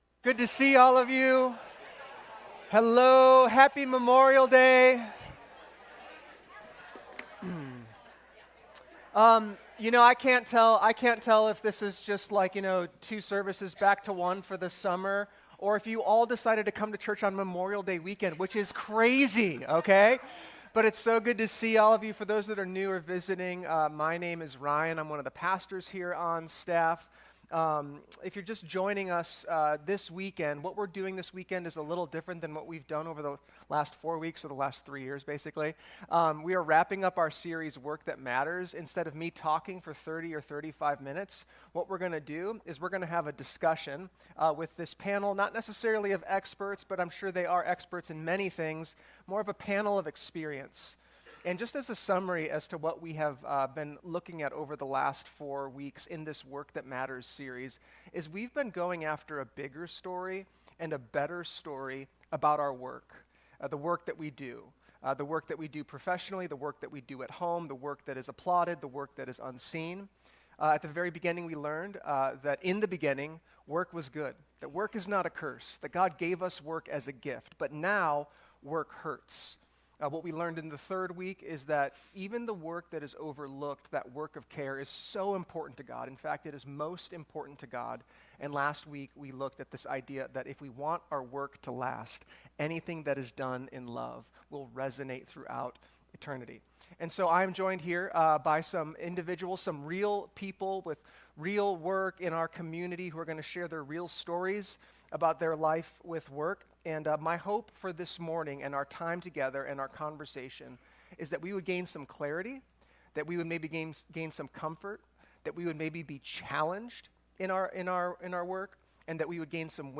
work-that-matters-panel-discussion.mp3